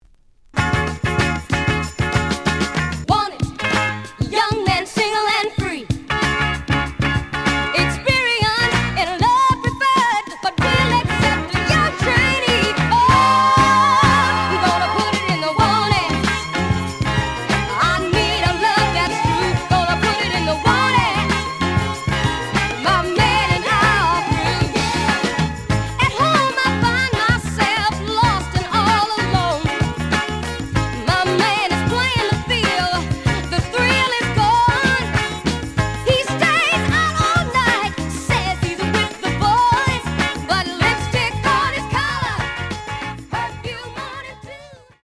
The audio sample is recorded from the actual item.
●Genre: Soul, 70's Soul
Slight damage and writing on both side labels. Plays good.)